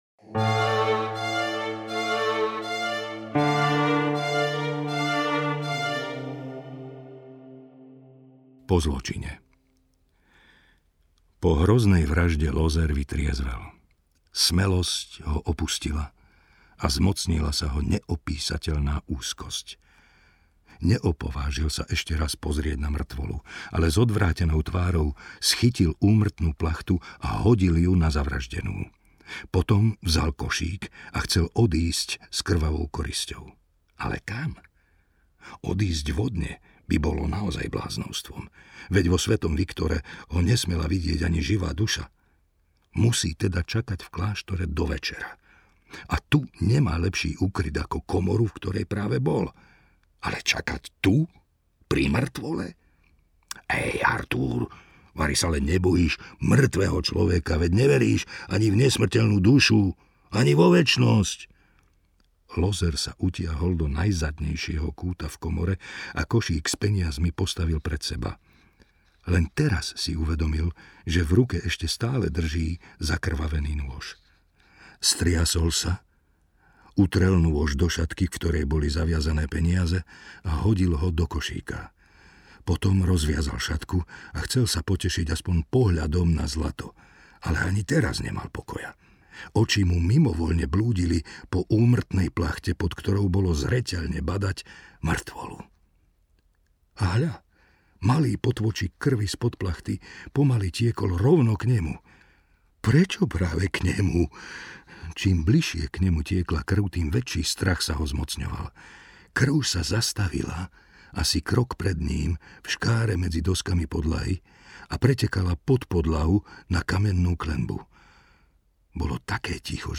Obe� spovedn�ho tajomstva/audiokniha